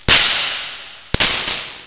Static2
STATIC2.WAV